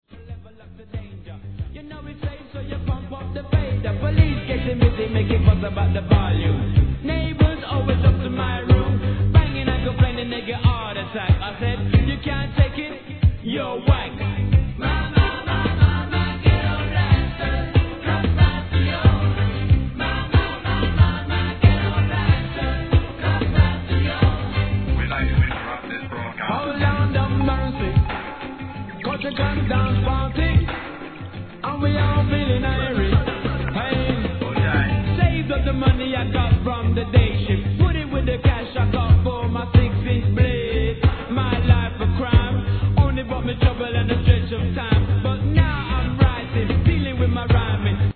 HIP HOP/R&B
バンド・スタイルでのキャッチーでPOPな作風は好印象。